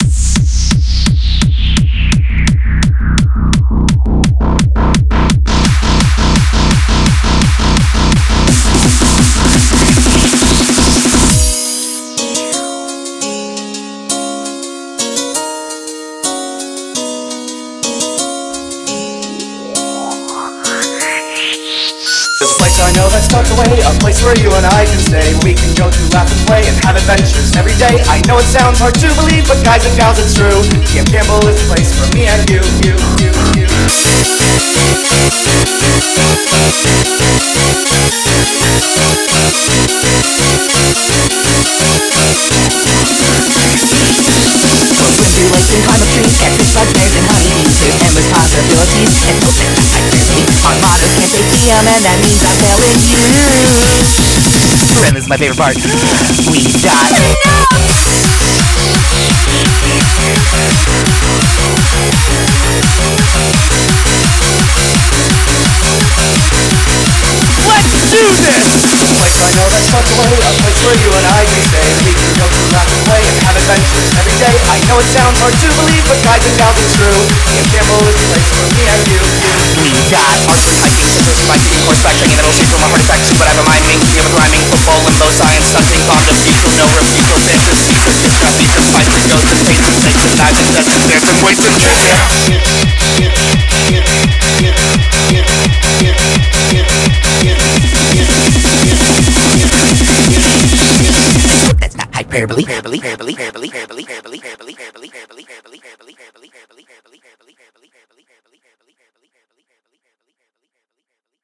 UK style
BPM170
Audio QualityPerfect (High Quality)